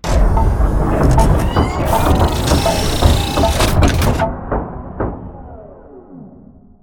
repair.ogg